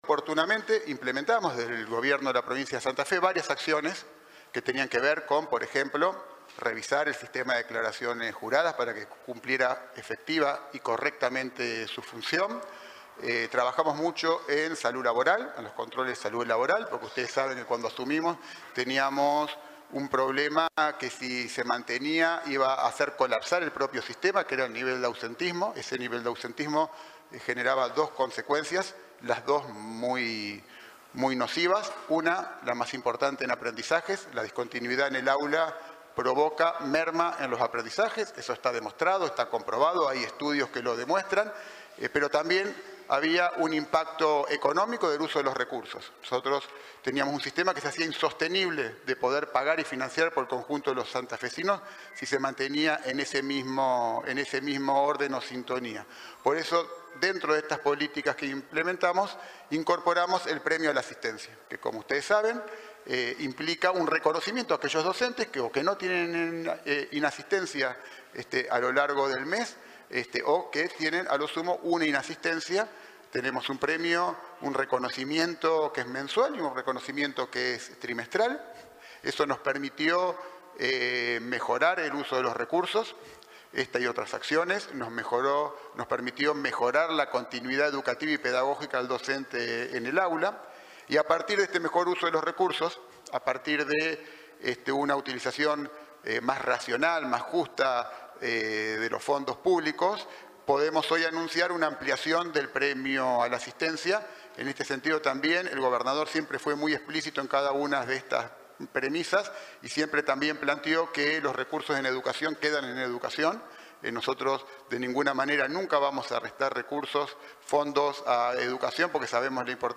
Así lo anunciaron este miércoles los ministros de Educación, José Goity, y de Gobierno e Innovación Pública, Fabián Bastia, durante una conferencia de prensa que brindaron en Casa de Gobierno, en la ciudad de Santa Fe, en la que también se confirmó que el próximo lunes 24 de febrero dará inicio el ciclo lectivo en toda la provincia.